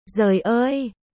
ベトナム語発音
ベトナム語で「驚いた」という表現その②：Giời ơi（ゾイオーイ）
Trời ơiと響きは似ていますが別の単語です。